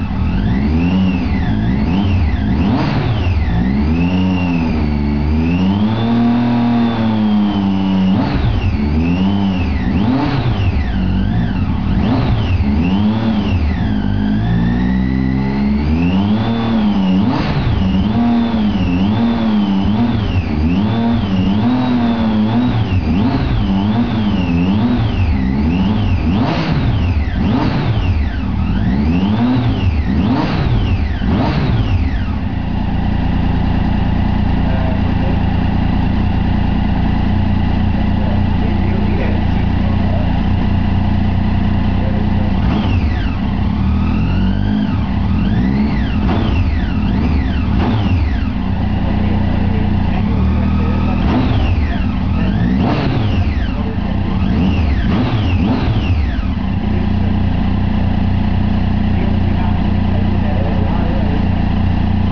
Kun lyd: Daytona 675 med standardpotte, varmer op. (0,6 MB)
Og lyden er bare dejlig.